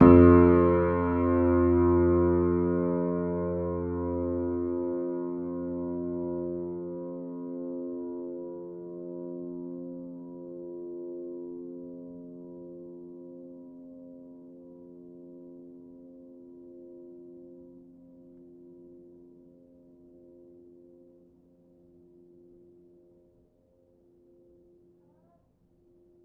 Upright Piano